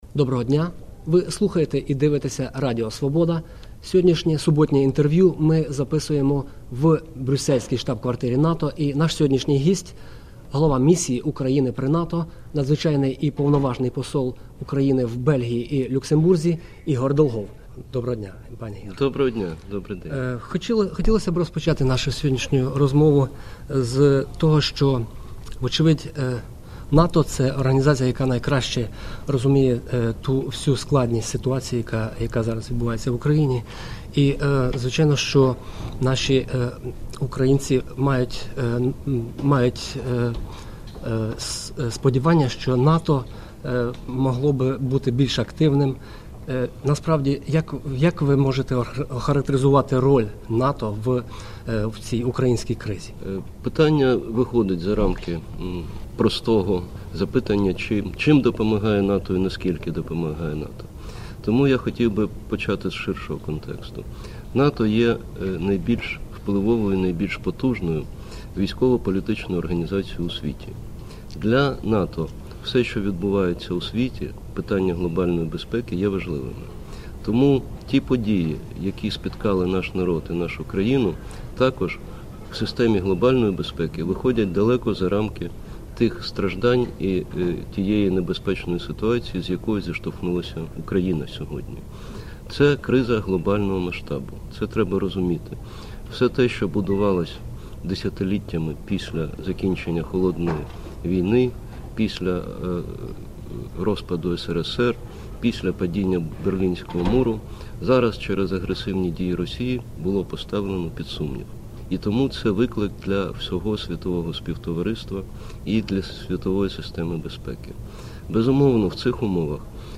Суботнє інтерв'ю